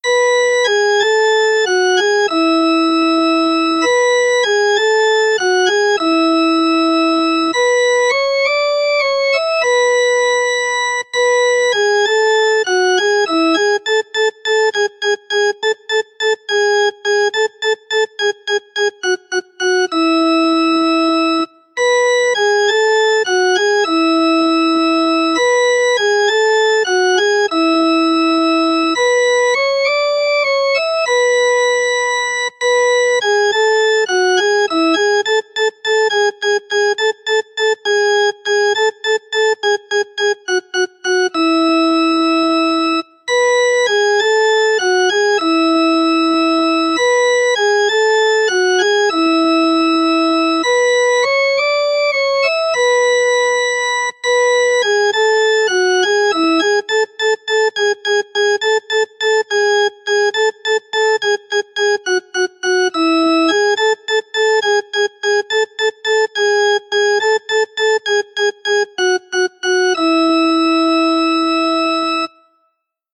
Instrumentu bertsioak: Me casó mi madre Nuestro “burriquito” Ya viene la vieja Una niña bonita Etzen bada Maria Lendabiziko ori Amaren xango Basoilarrak Kukurruku!
ya viene la vieja inst masterizada.mp3